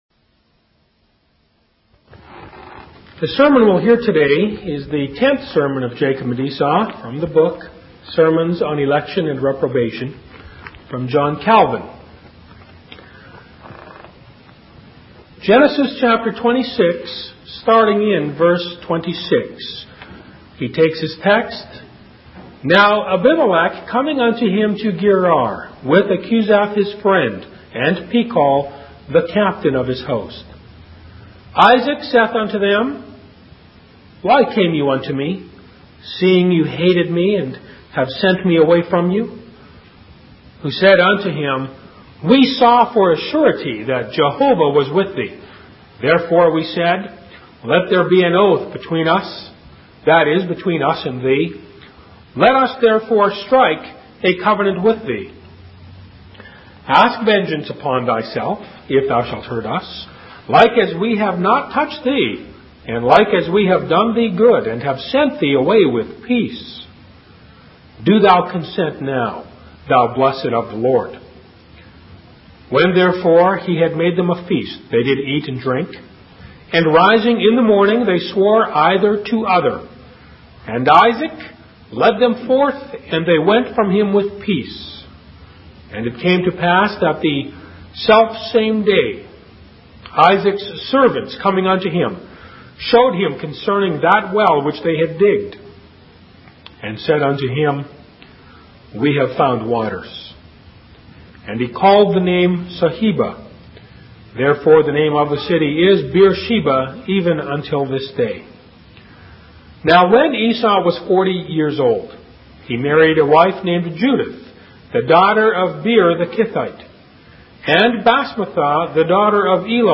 In this sermon, John Calvin focuses on the story of Isaac and Abimelech in Genesis 26. He emphasizes the importance of separating oneself from those who despise God, as their influence can lead to spiritual destruction. Calvin also highlights the need for reconciliation and humility when wronging others, emphasizing the importance of confessing and seeking forgiveness.